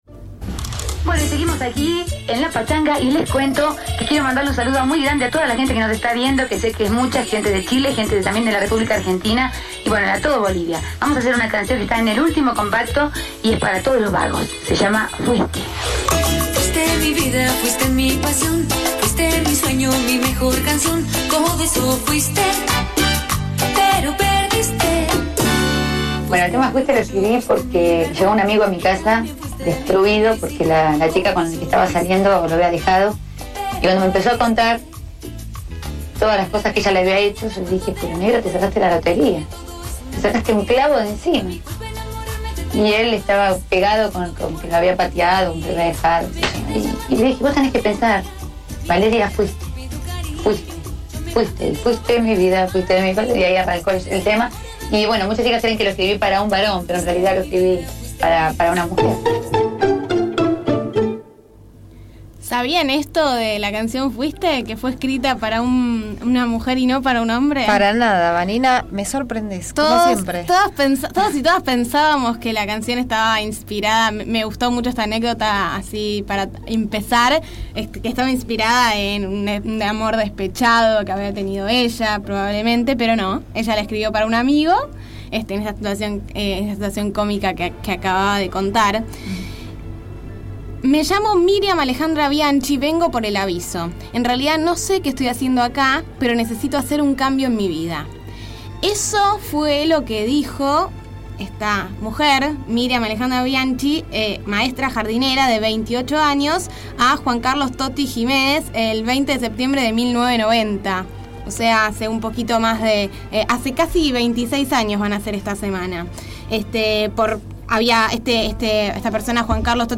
20 años sin Gilda | Informe